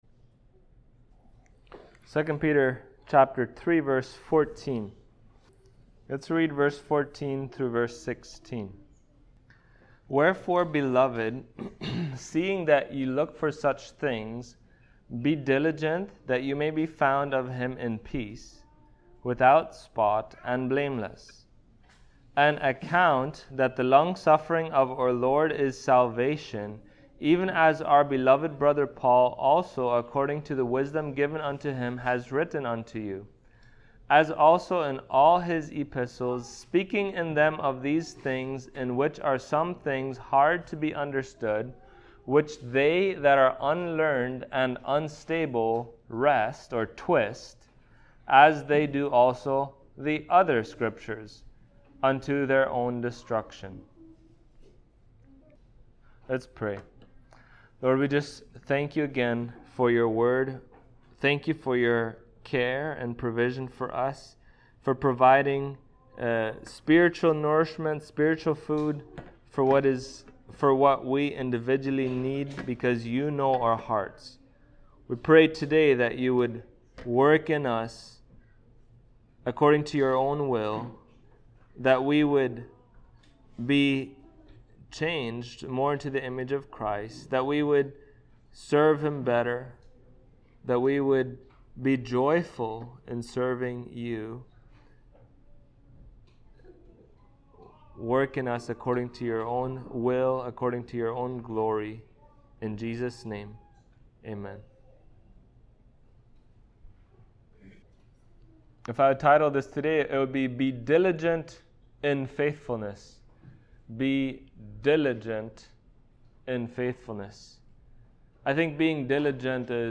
2 Peter 3:14-16 Service Type: Sunday Morning Topics